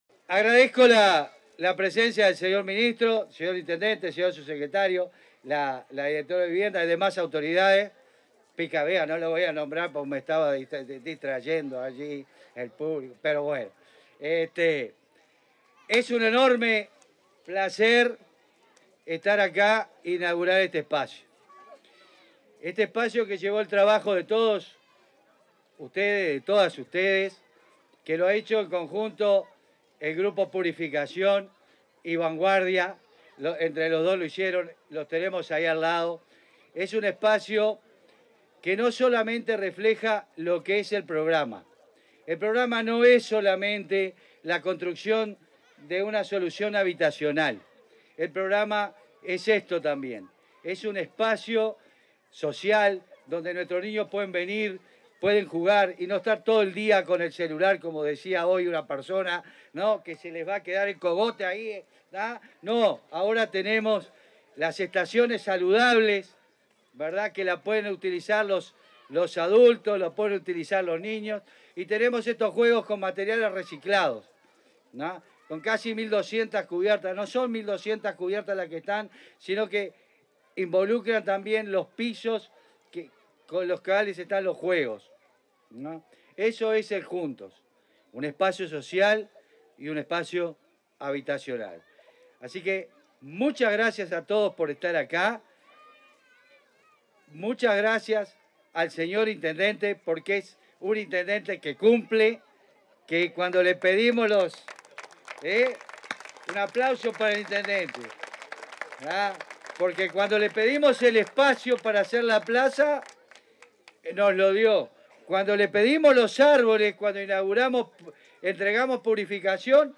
Palabras del ministro de Vivienda y del coordinador de Juntos
Palabras del ministro de Vivienda y del coordinador de Juntos 21/09/2023 Compartir Facebook X Copiar enlace WhatsApp LinkedIn En el marco de la ceremonia de inauguración del espacio de juegos infantiles Cimarrón III, este 21 de setiembre, se expresaron el coordinador de Juntos, Rody Macías, y el ministro de Vivienda y Ordenamiento Territorial, Raúl Lozano.